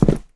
landing sound
jumpland4a.wav